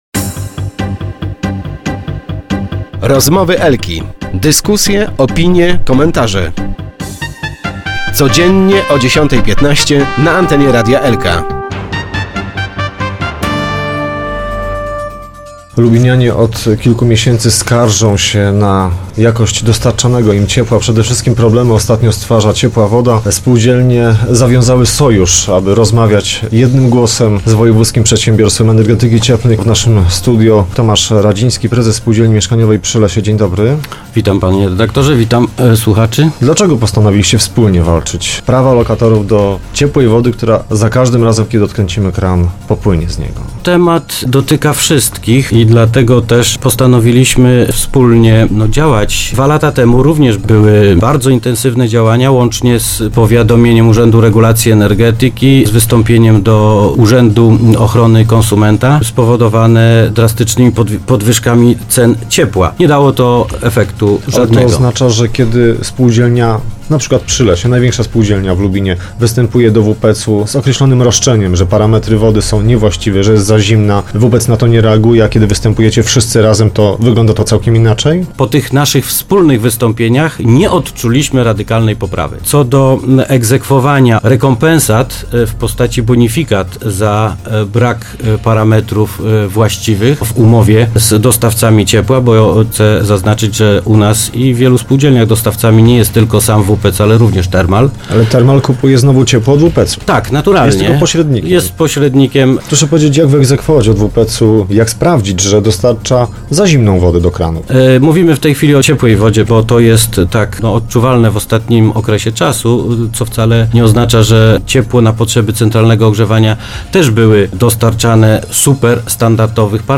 radio, głogów, głogowski, Lubin, lubiński, miedz, miedziowe, portal regionu, elka